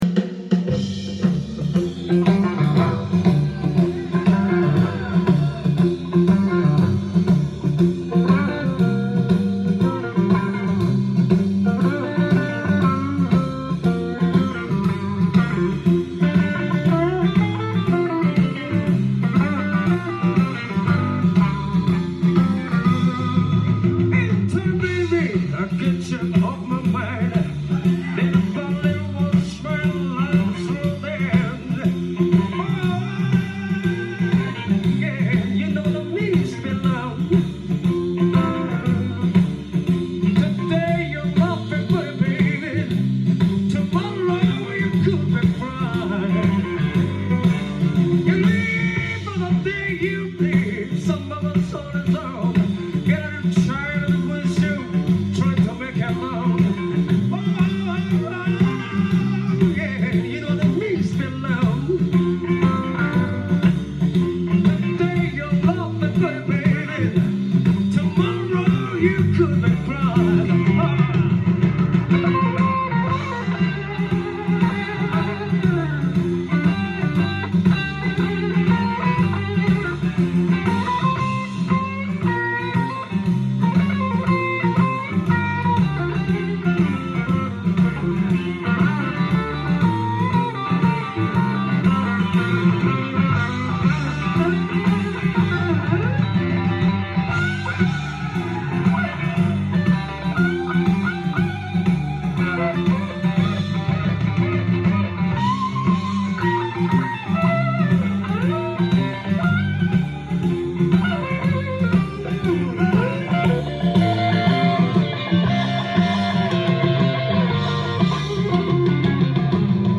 ジャンル：J-BLUES
店頭で録音した音源の為、多少の外部音や音質の悪さはございますが、サンプルとしてご視聴ください。